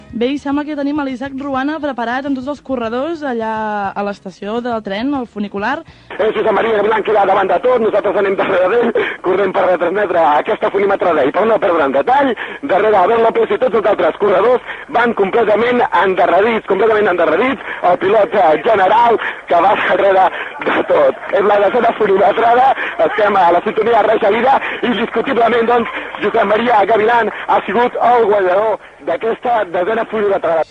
Transmissió de l'arribada de la cursa Funimetrada de Gelida (una cursa de 101 metres de desnivell positiu que es recorre en gairebé una mica més de 1600 metres)
Esportiu